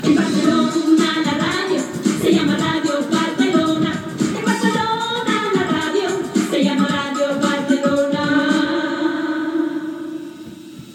Indicatiu cantat de l'emissora